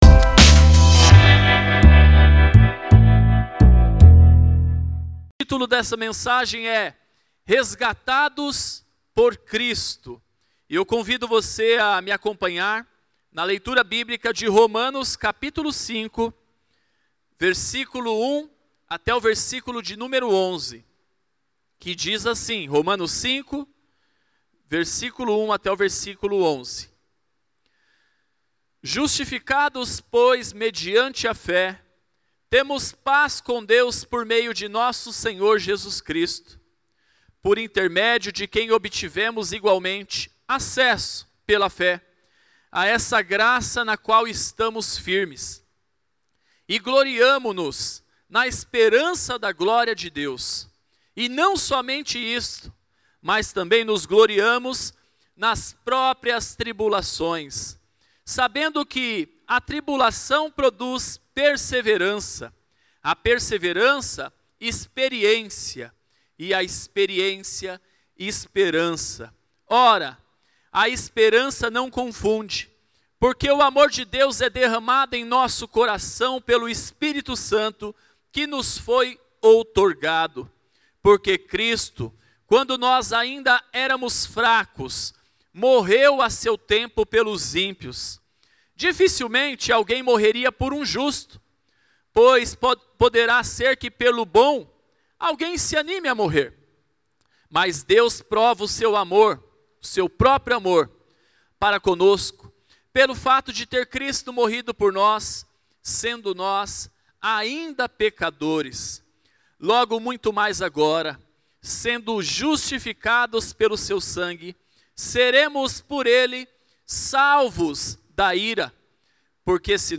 Mensagem ministrada
no Domingo de Páscoa de 2021.